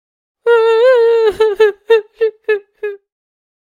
cry.ogg.mp3